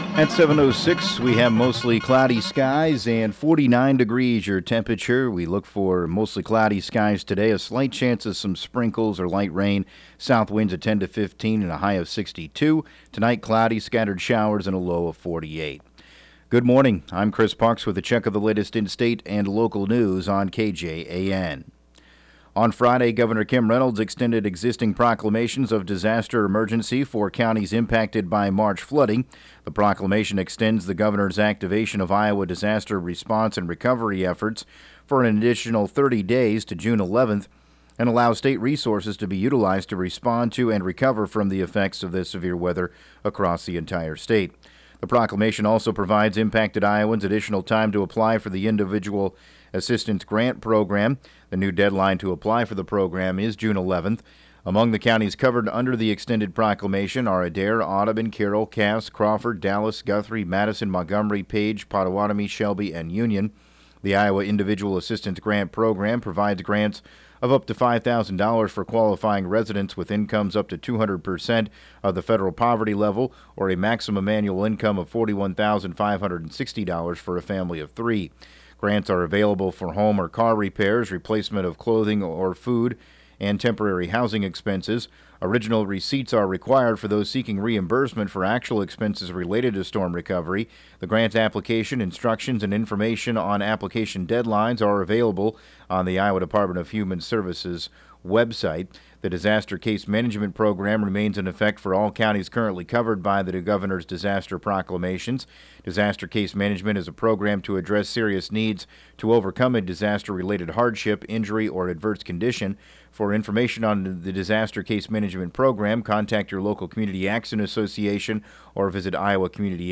7AM Newscast 05/11/2019